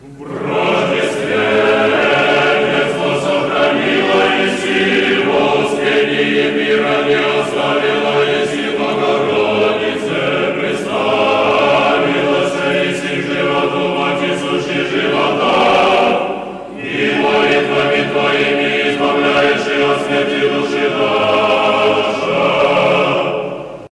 Тропарь Успения Пресвятой Богородицы